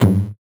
CS_VocoBitA_Hit-09.wav